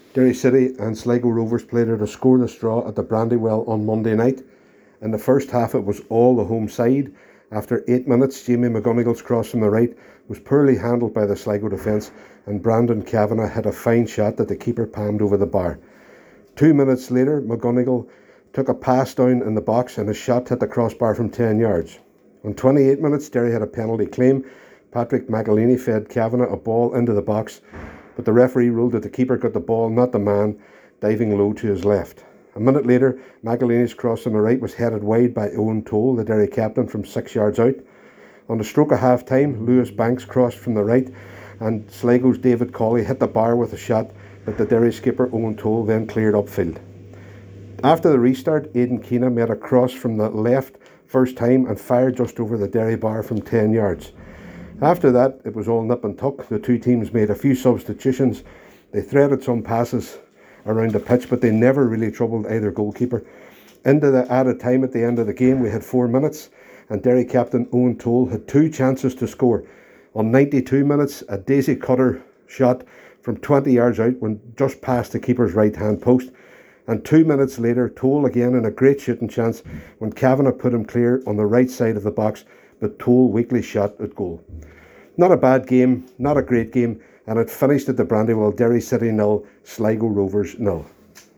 reports from the Ryan McBride Brandywell Stadium